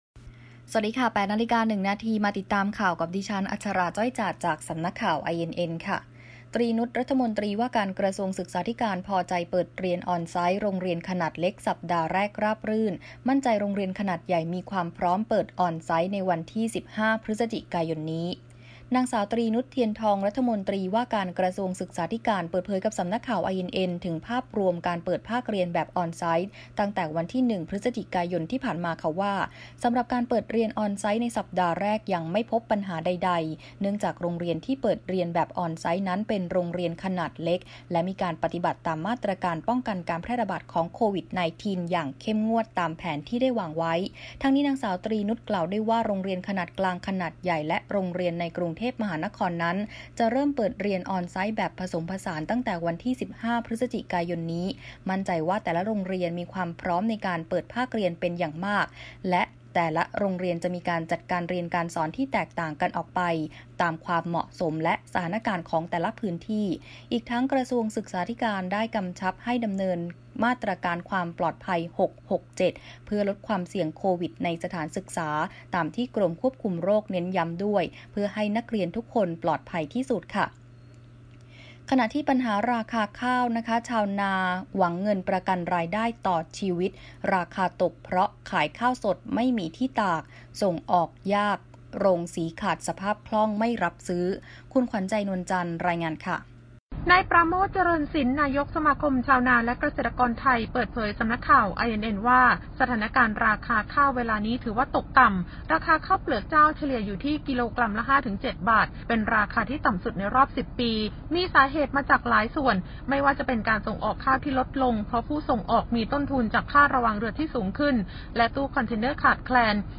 คลิปข่าวต้นชั่วโมง
ข่าวต้นชั่วโมง 8.00 น.